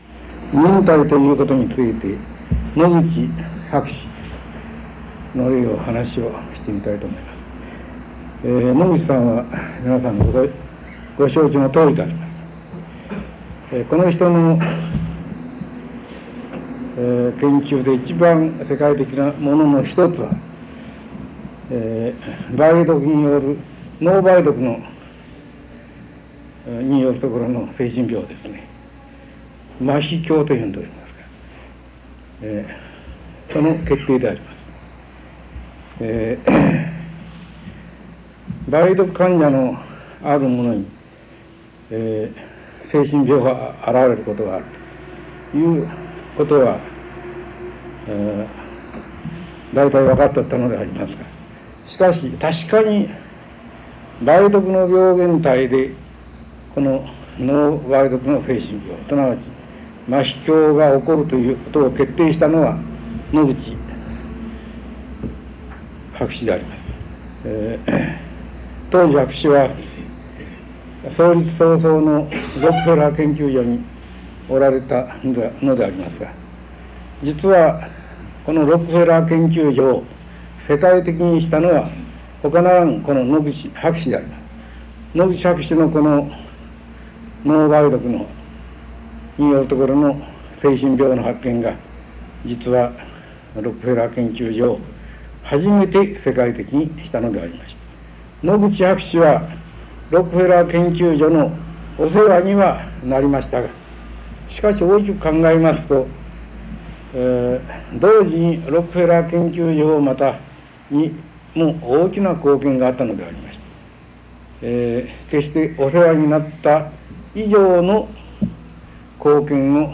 京大元総長の平澤興氏が繰り広げる、白熱の人間学講話、全３巻。
※この音声は、昭和45年から49年に新学社にて平澤氏が行った講話をカセットテープで録音したものです。 ※再生機によって、部分的に音声が聞き取りづらい箇所があります。